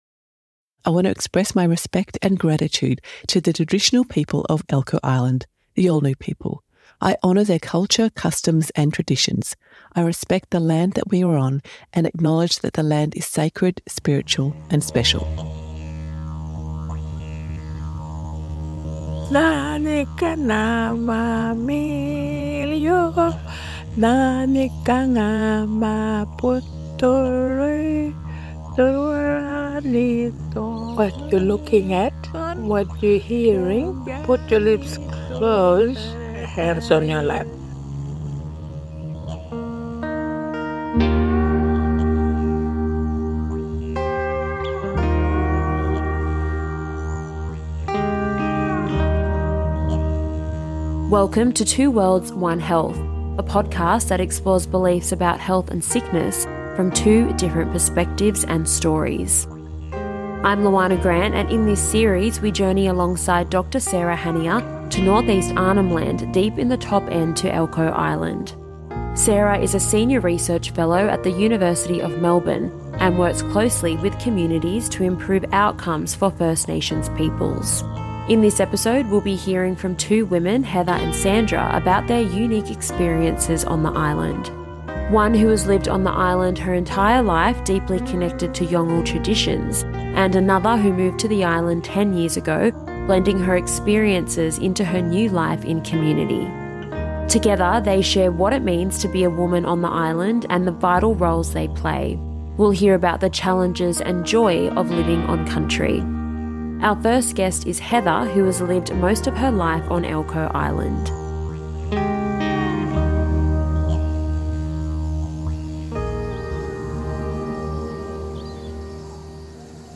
What can two women - one who’s lived in Galiwin’ku most of her life, and one who made it home - teach us about holding knowledge, building community, and navigating change in a remote Yolŋu community? In this episode, we hear from two women with different journeys but a shared commitment to community.